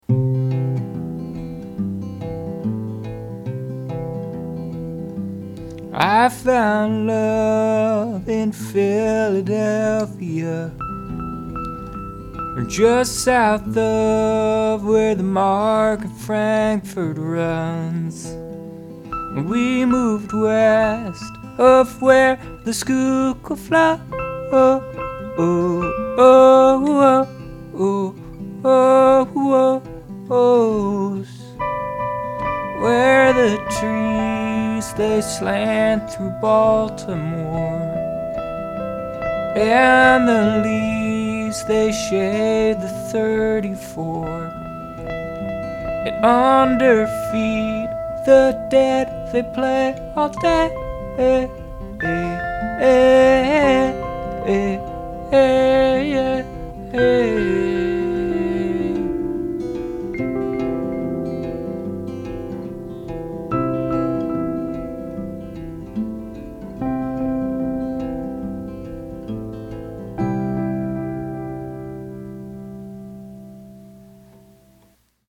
intro C, C/E, C/G, C/A C, C/E, C/G, C/A
verse1 Am, G, C Am, G, C Am, G, C, F, C, G7
intro verse verse outro
on a less sentimental note, it's the melody that wins in this one. the leaps and lopes when extending the final words of each stanza are the signature successes in this tune, to my ears. the the walking low note in the fingerpicked part is killer, and its simplicity and repetitiveness ground the proceedings. the keyboard part and its treatment are also lovely and properly understated.